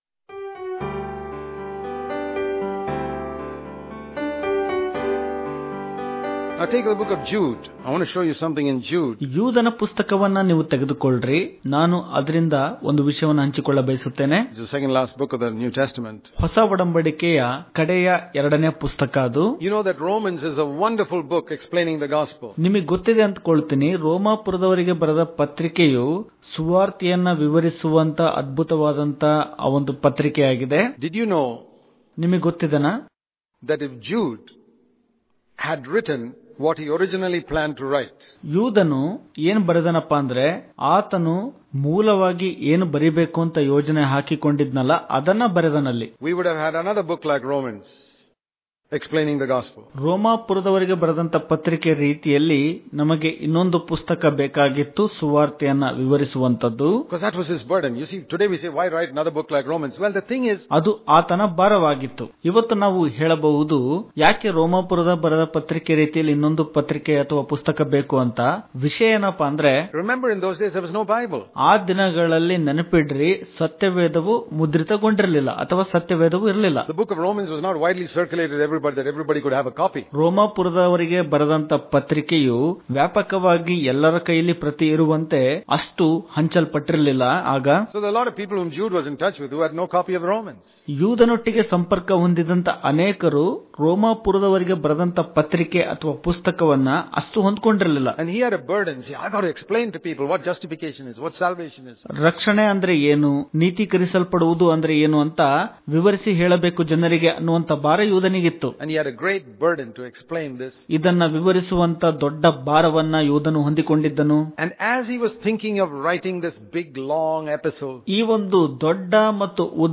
November 12 | Kannada Daily Devotion | Grace Is Not a License to Sin Daily Devotions